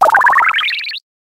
flittle_ambient.ogg